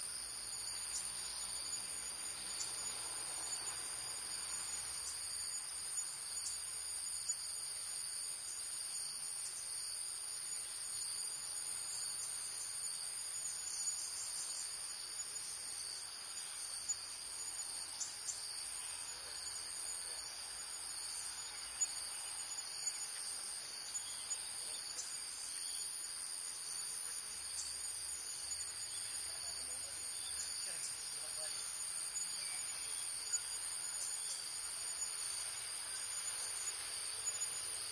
Thailand » Thailand jungle night crickets +water knocks boat hull1
描述：Thailand jungle night crickets +water knocks boat hull
标签： boat Thailand night jungle crickets water fieldrecording
声道立体声